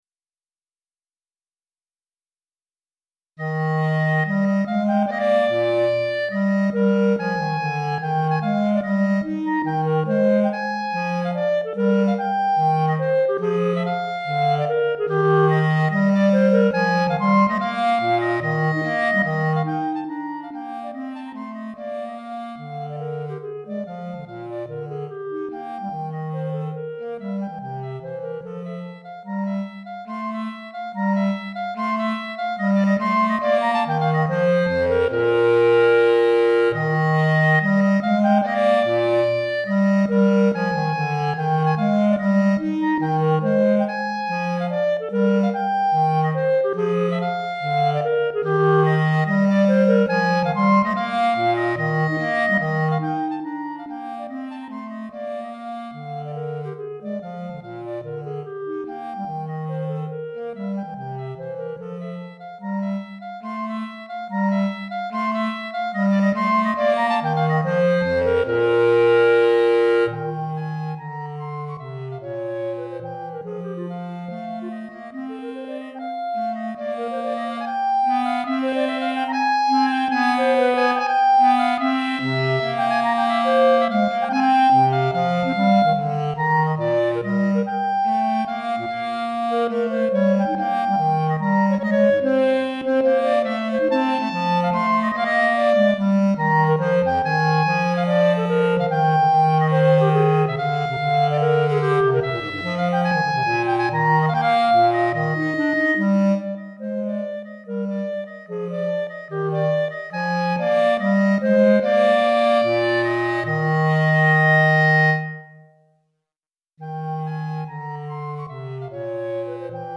Clarinette en Sib et Clarinette Basse